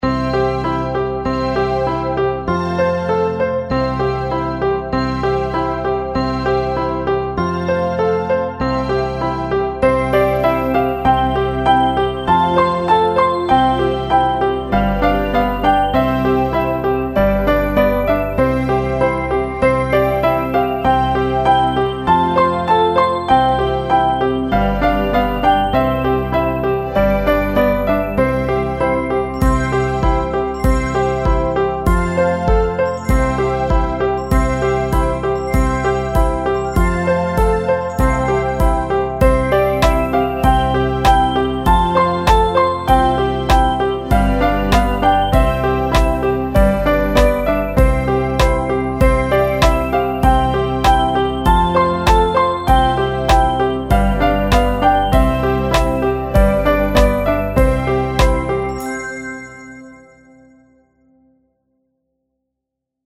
Canción Infantil, España